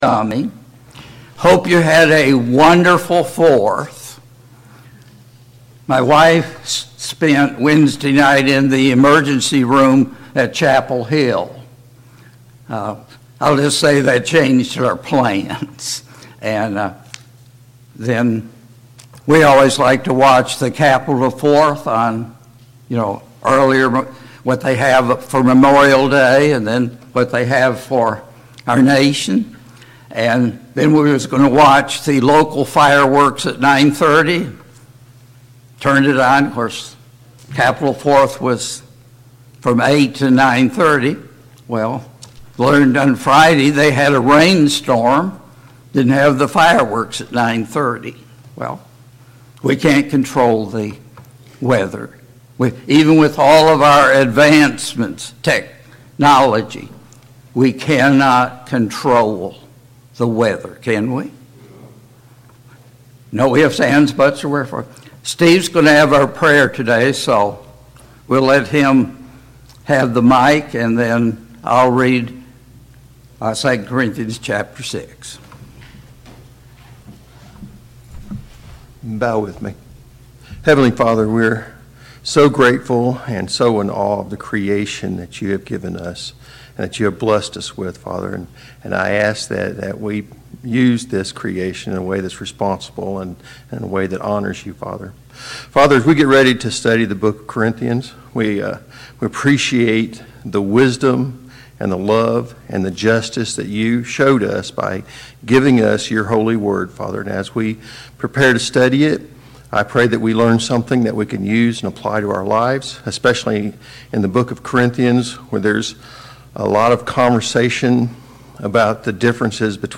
Sunday Morning Bible Class « 14.